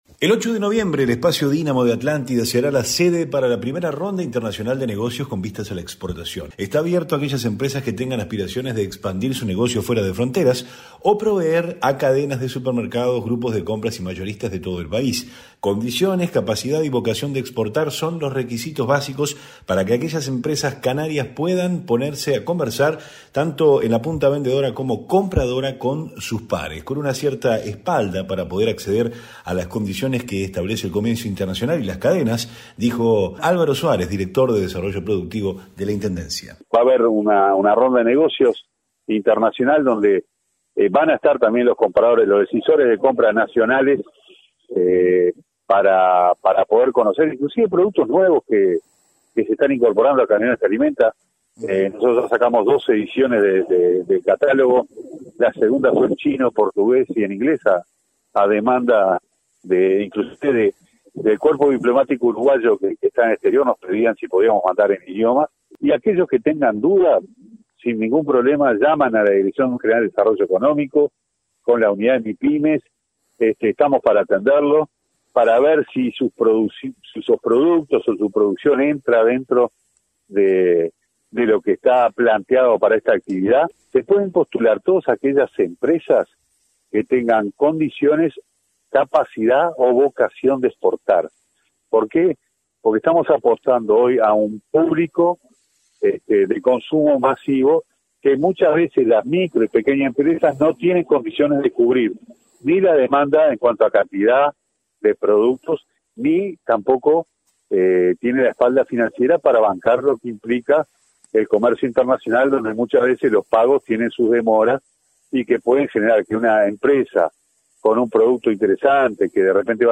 REPORTE-ENCUENTRO-INTERNACIONAL-DE-EMPRESAS.mp3